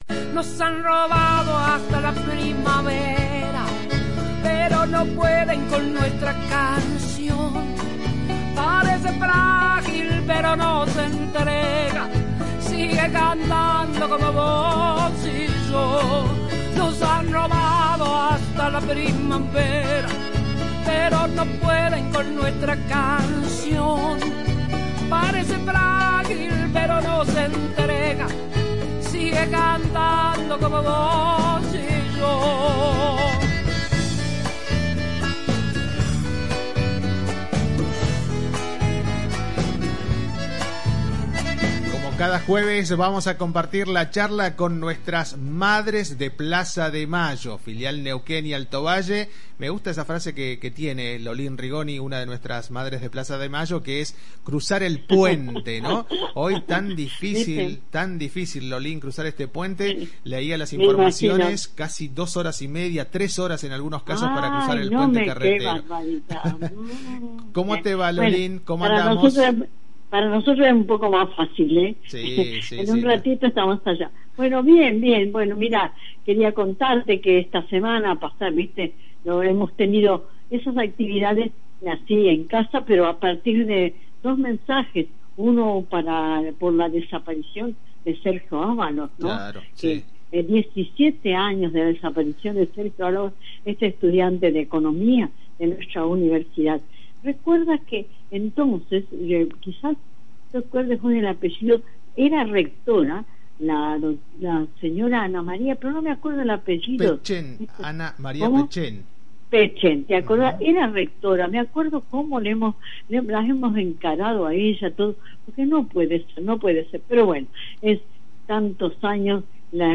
Hoy en comunicación telefónica